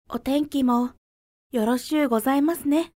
女性_「どうか私をお許しください」
00:00 / 00:00 女性_「どうか私をお許しください」 作者： カテゴリー タグ： ボイス 女性 ファイルタイプ： mp3 ファイルサイズ： 55 KB ダウンロード 素材倉庫について ボイス 00:00 / 00:00 ダウンロード 女性_「涙が止まらなくても、前に進むしかない。」